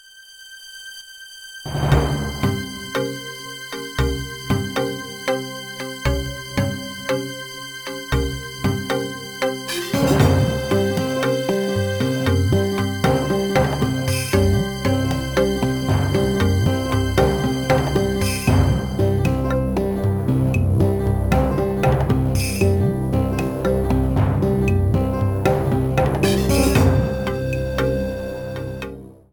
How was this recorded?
Applied fade-out.